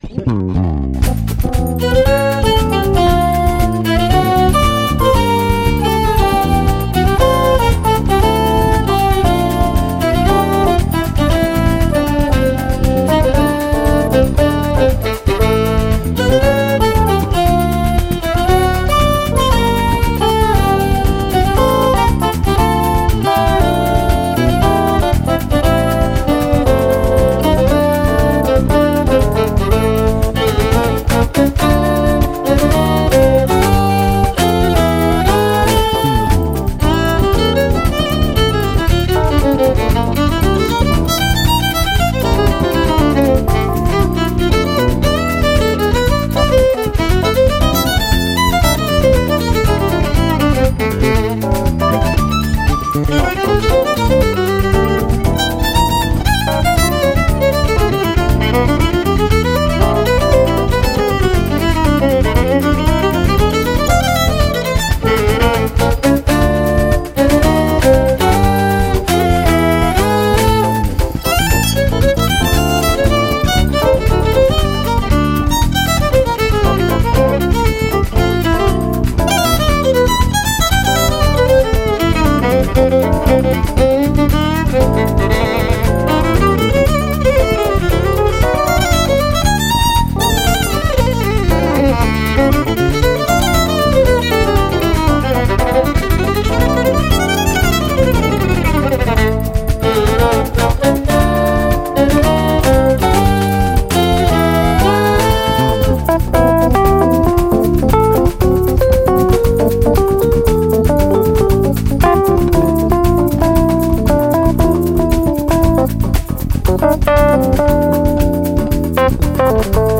CD v súčasnom štýle groove-fusion-smooth-nu-...-jazz.
klávesy